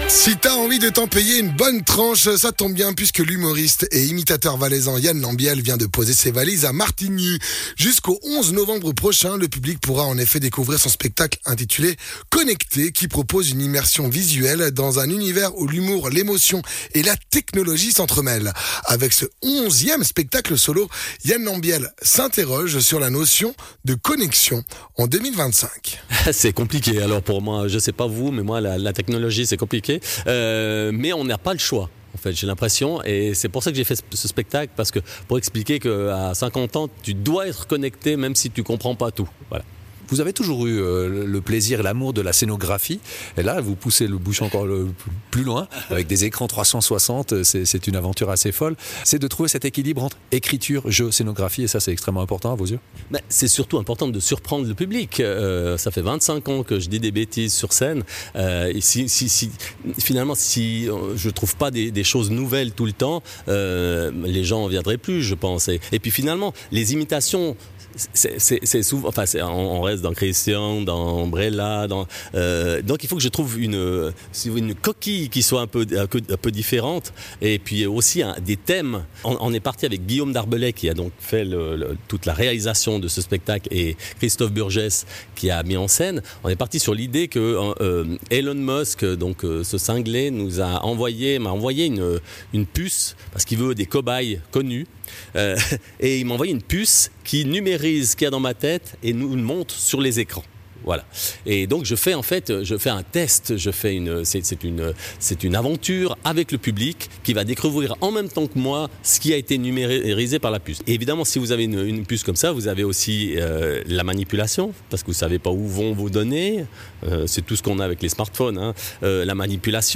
Intervenant(e) : Yann Lambiel, humoriste et imitateur